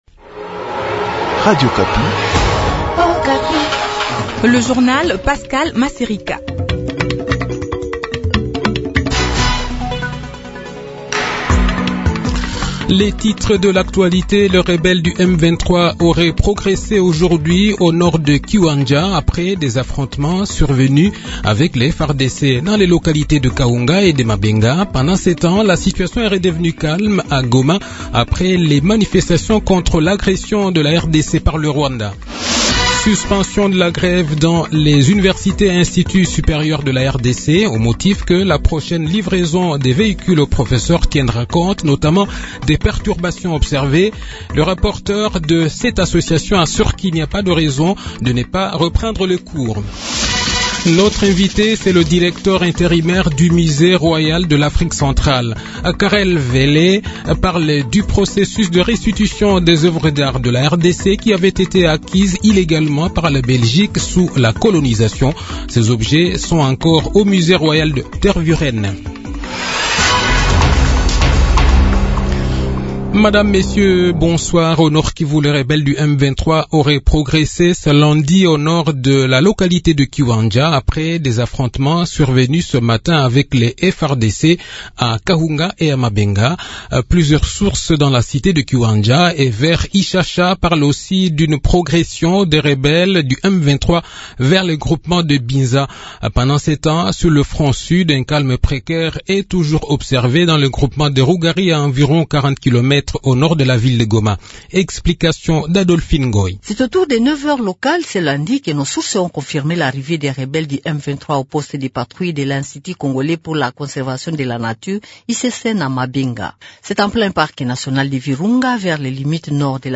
Le journal de 18 h, 31 octobre 2022